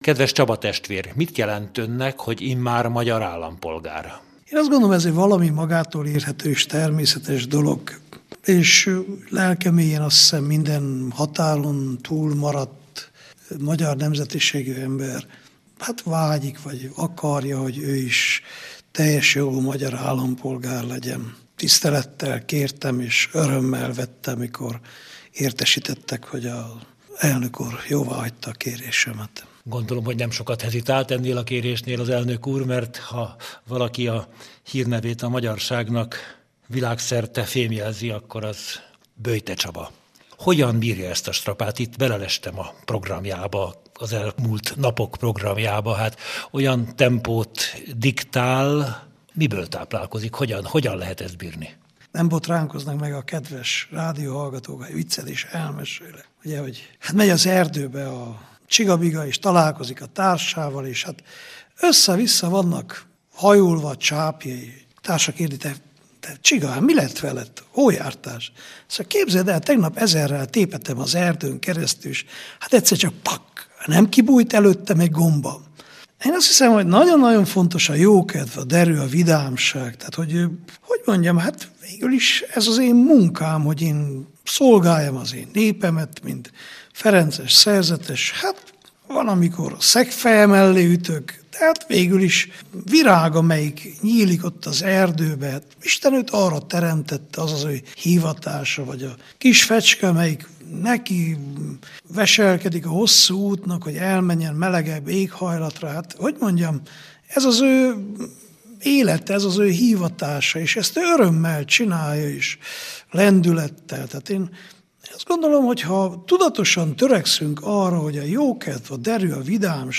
Csaba testvér az ötszázezredik, aki felvette a magyar állampolgárságot. Ebből az alkalomból beszélt hivatásáról a Lánchíd rádió Hitvilág című műsorában december 8-án. Az interjút itt meghallgathatja.